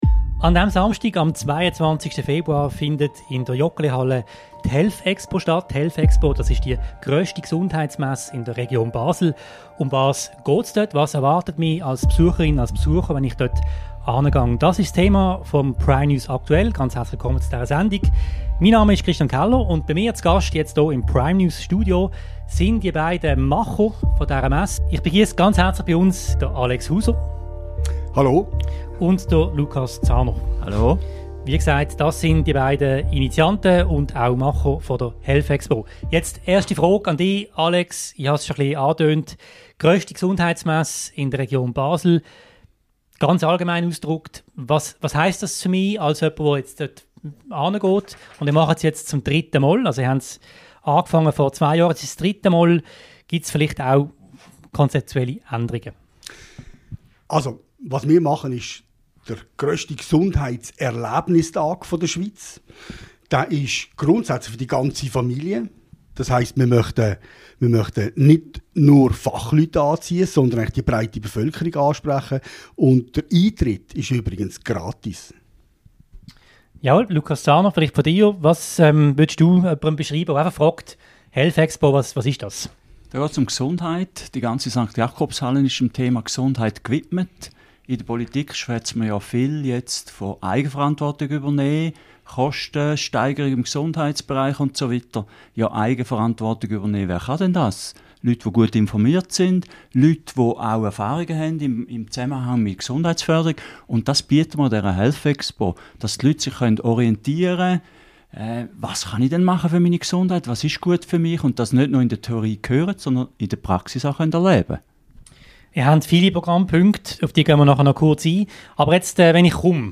Moderation
im Intervew